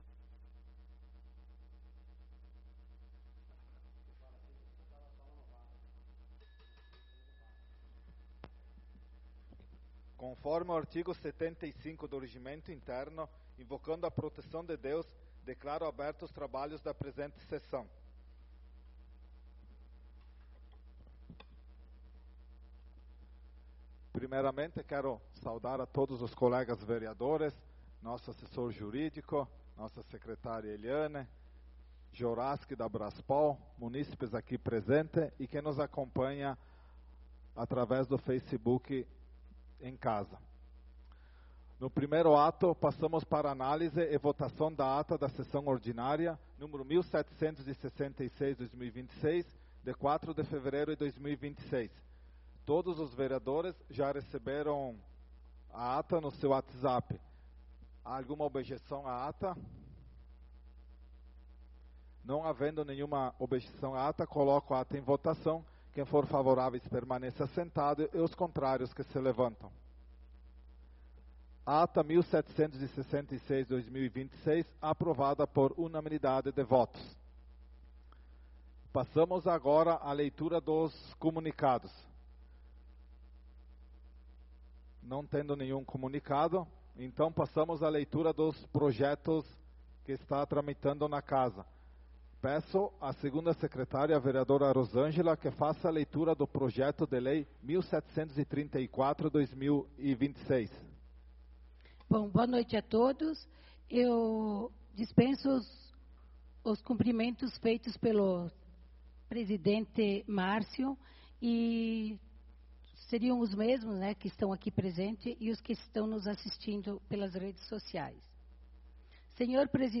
Sessão Ordinária do dia 11/02/2026